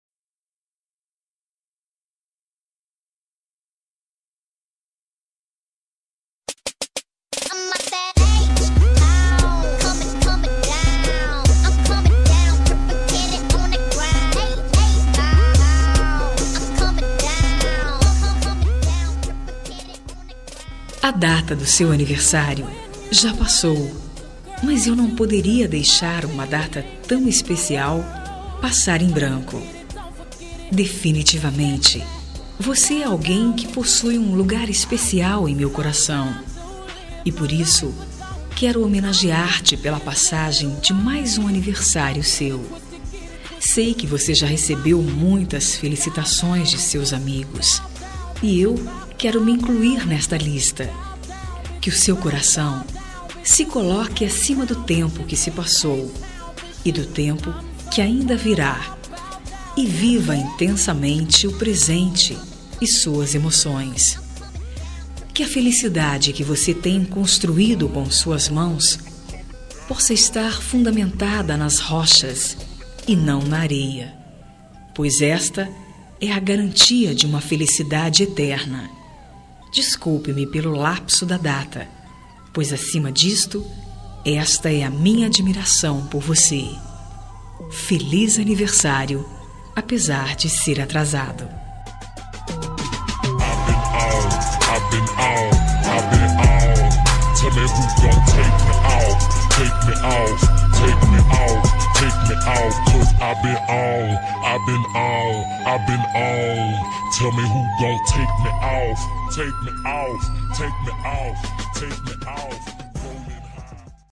Voz Feminina